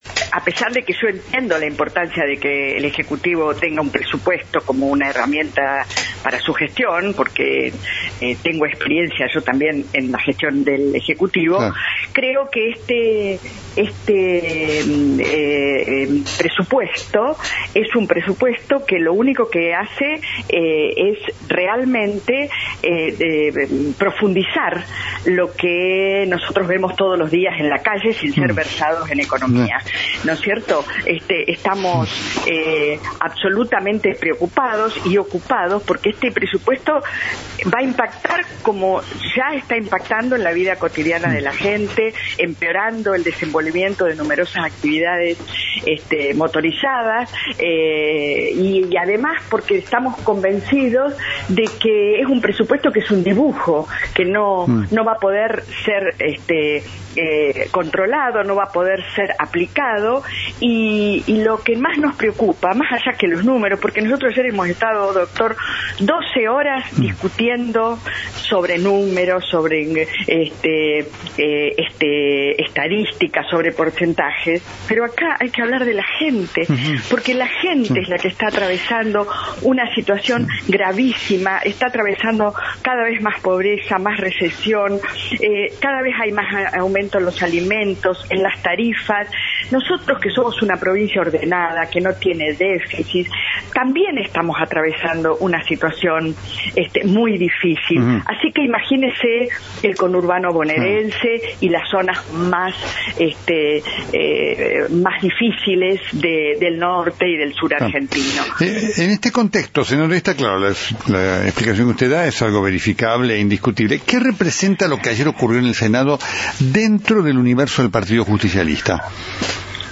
Luego de la aprobación del Presupuesto 2019, la senadora pampeana, Norma Durango, explicó porque votó negativamente este proyecto que "lo único que hace, es profundizar lo que vemos en la calle", "Estamos muy preocupados por Presupuesto 2019, va a empeorar todo". También adelantó que no aprobará el pedido de desafuero de Cristina Fernández.